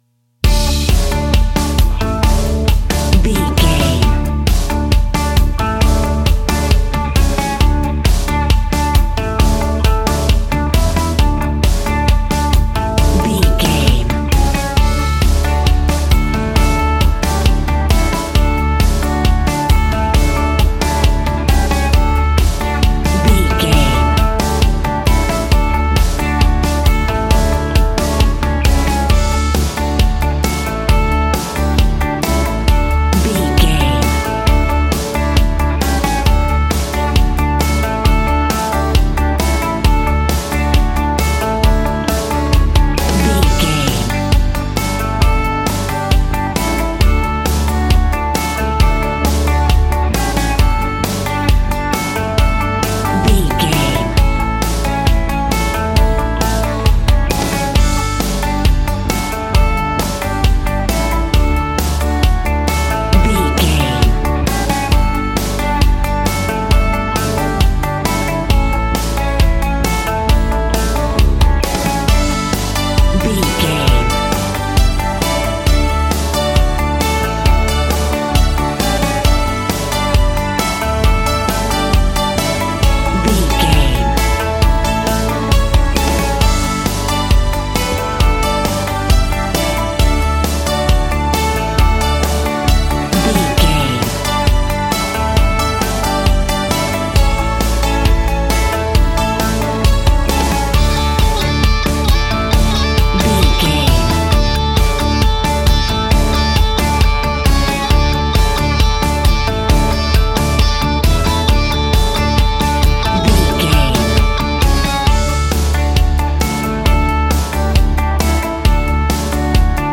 Ionian/Major
groovy
happy
electric guitar
bass guitar
drums
piano
organ